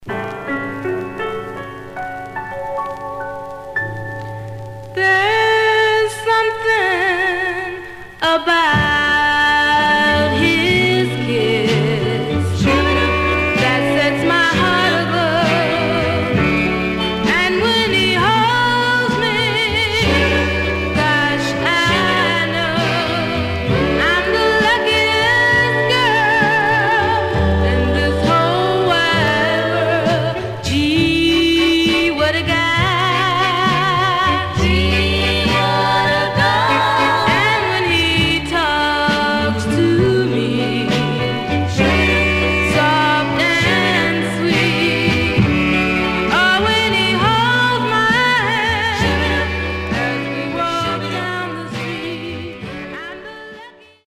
Some surface noise/wear Stereo/mono Mono
Black Female Group